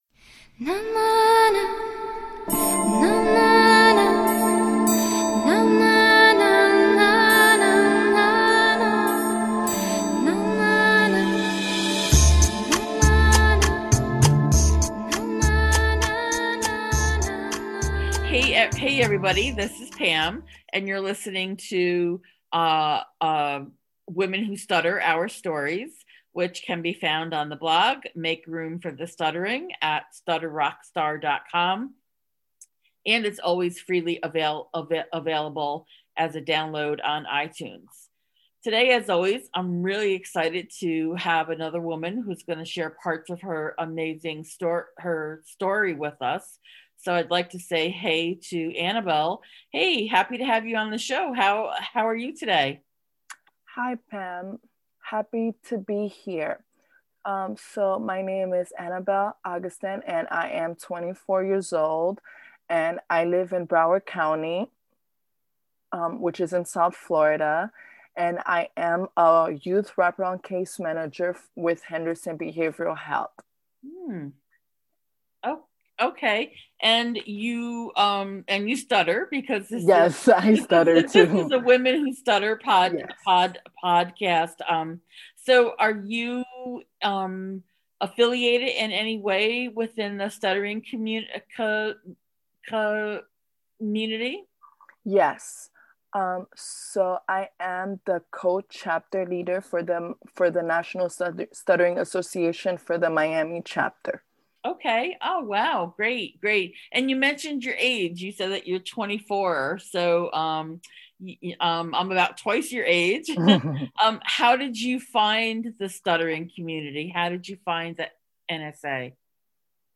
What a great conversation.